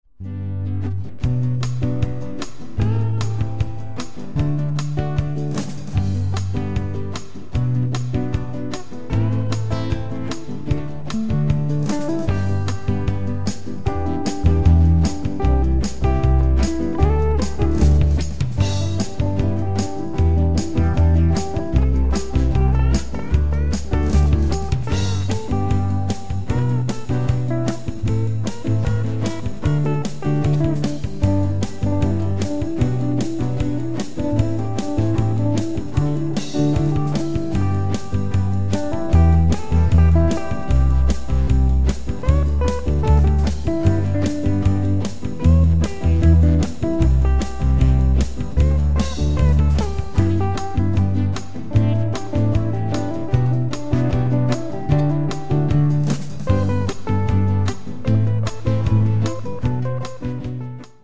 wieder ein Gitarrensong. Ein Duett zweier Washburn Gitarren.